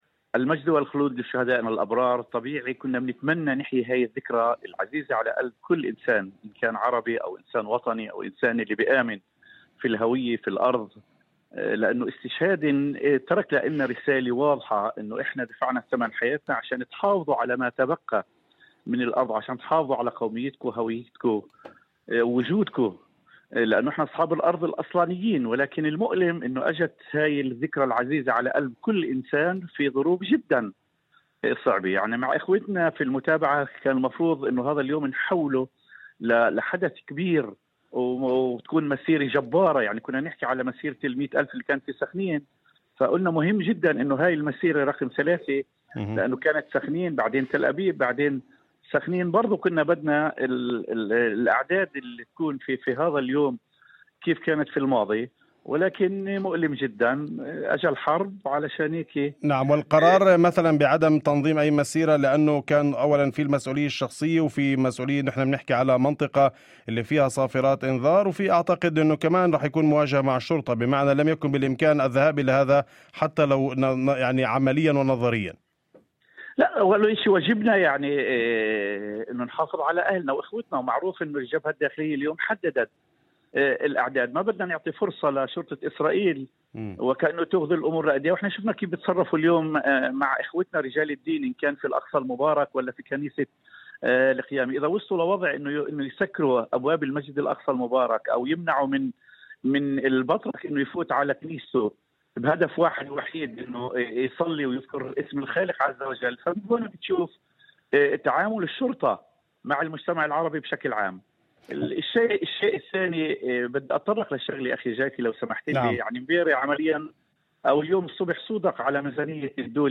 وأضاف في مداخلة هاتفية ضمن برنامج "أول خبر" على إذاعة الشمس، أن قرار إلغاء المسيرات جاء نتيجة "المسؤولية تجاه الناس"، في ظل وجود صافرات إنذار، إلى جانب مخاوف من احتكاكات مع الشرطة، ما جعل من الصعب تنظيم فعاليات واسعة كما في السنوات السابقة.